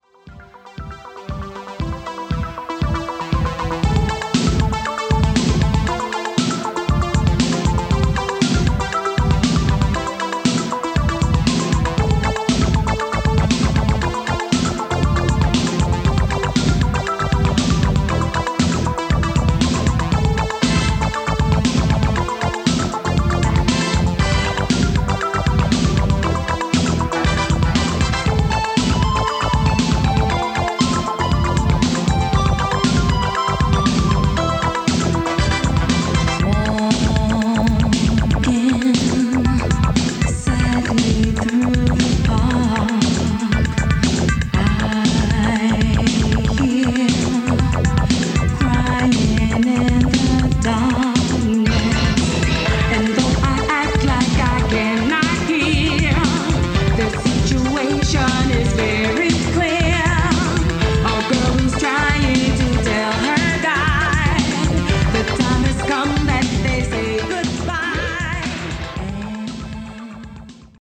The built in HX Pro makes sure that recordings got crisp highs and on the noise reduction end we also got Dolby B & C.
Below is a test recording made with the CT-W501R and played back by it: